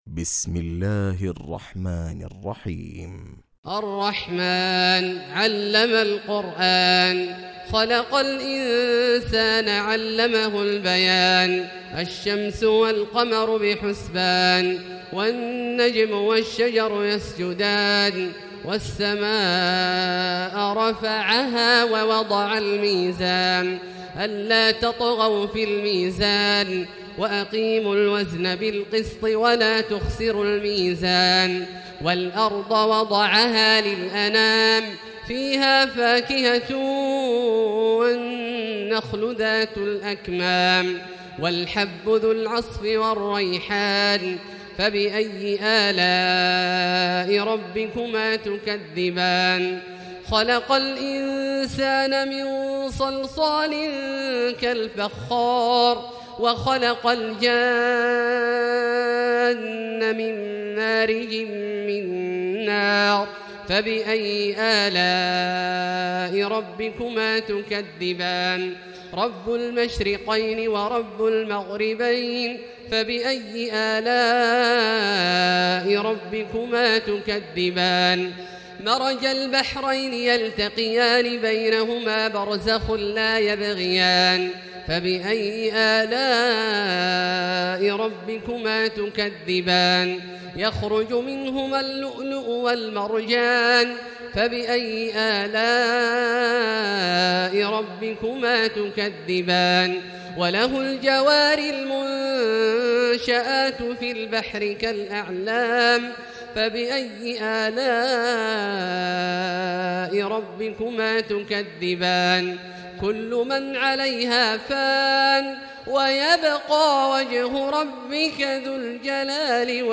تراويح ليلة 26 رمضان 1439هـ من سور الرحمن الواقعة و الحديد Taraweeh 26 st night Ramadan 1439H from Surah Ar-Rahmaan and Al-Waaqia and Al-Hadid > تراويح الحرم المكي عام 1439 🕋 > التراويح - تلاوات الحرمين